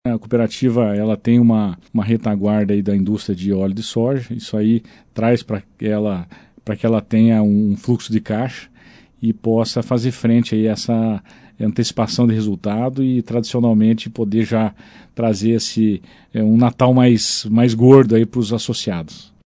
ENTREVISTA-COCAMAR-02.mp3